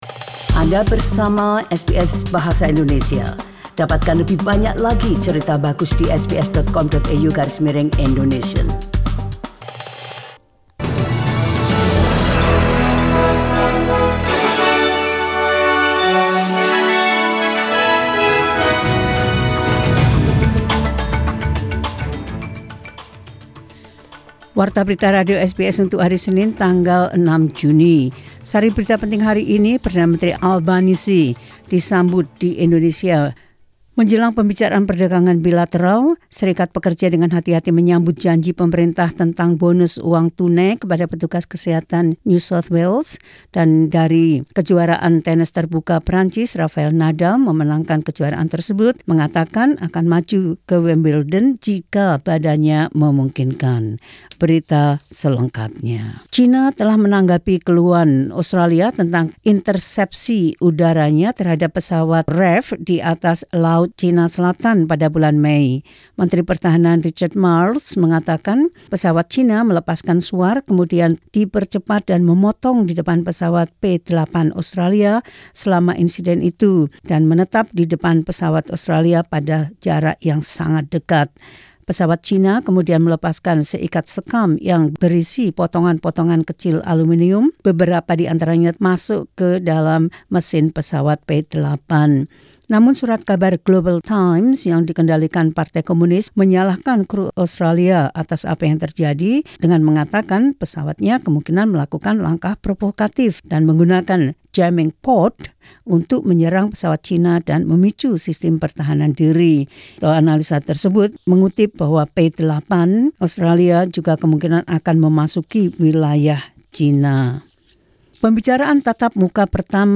Warta Berita Radio SBS Program Bahasa Indonesia – 06 Juni 2022
SBS News Indonesian Program – 06 June 2022.